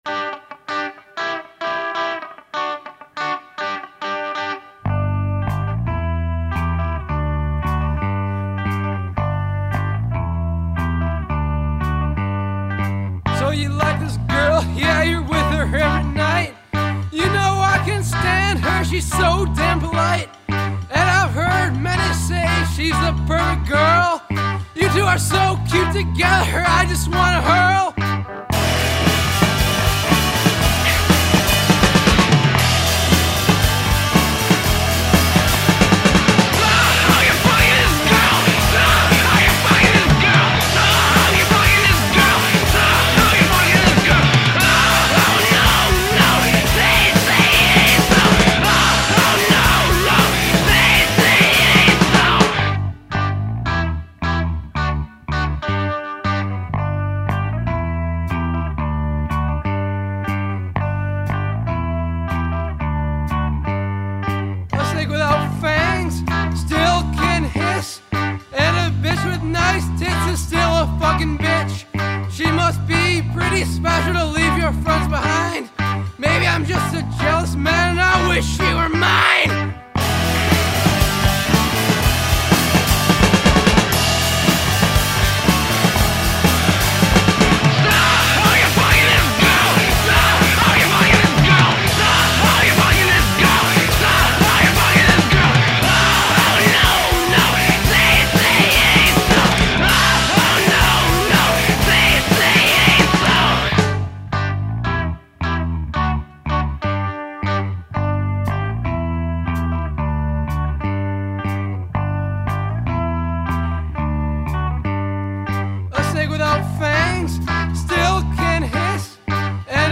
This is a happy little number
Music Style: Punk